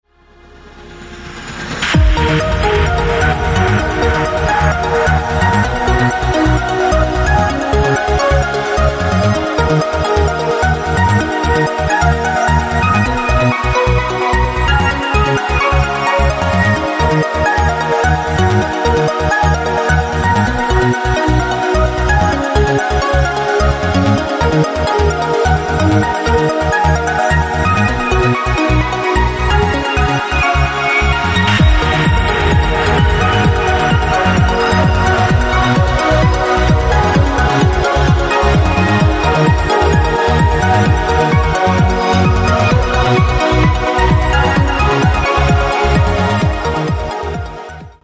Стиль: Progressive Trance Ура!